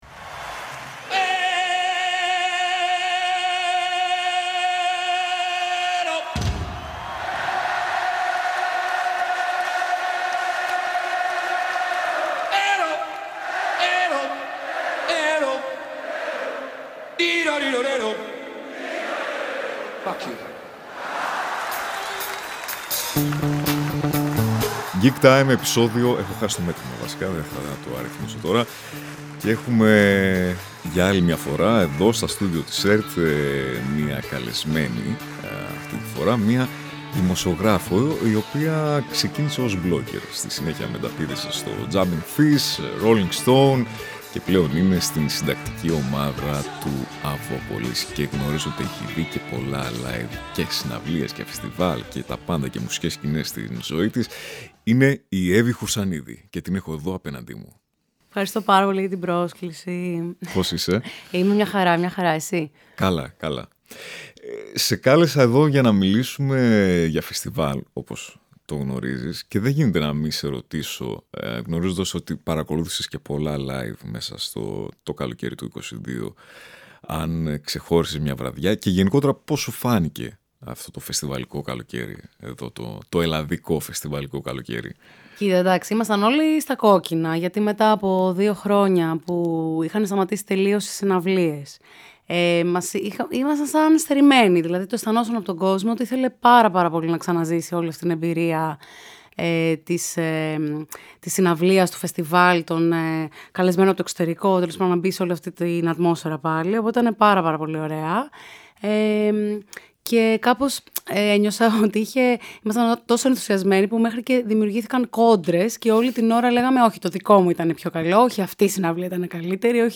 “Gig time”. Ένα podcast με την υπογραφή του Kosmos. Μια σειρά podcast για το -κατά πολλούς- πιο ενδιαφέρον και ζωντανό κομμάτι της μουσικής, το live.